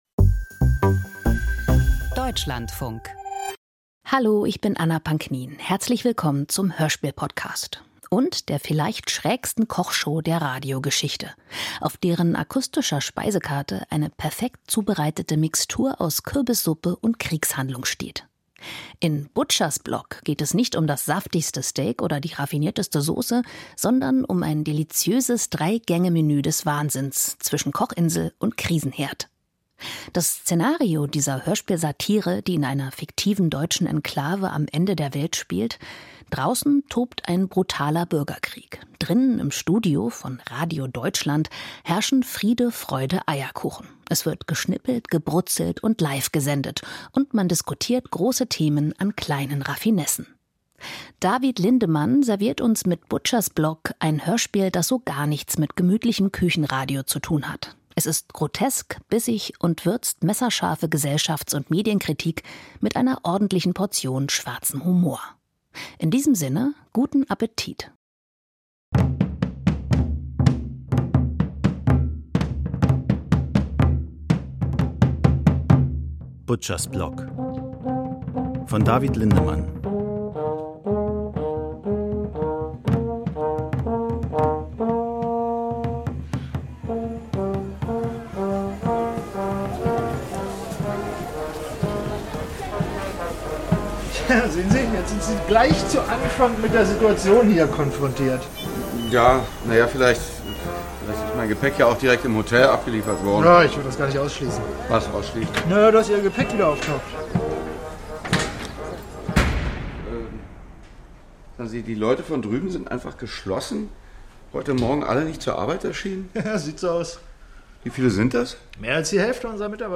Hörspiel nach Maurice Blanchot